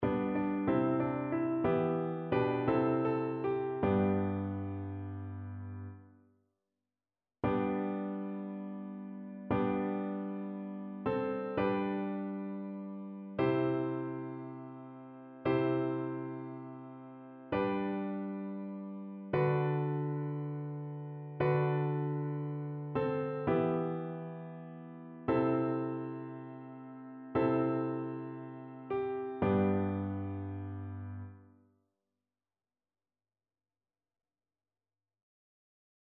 ChœurSopranoAlto
annee-abc-temps-du-careme-veillee-pascale-psaume-29-satb.mp3